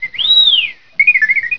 3. Representation of R2-D2 Sounds
For that reason I decided to use the HPS.m algorithm to determine the fundamental frequency of simple R2-D2 whistles that could be later modified in relation to the time-frequency plot of the voice input file, which would be produced via the fzero~ object in Max/MSP.
whistle1.wav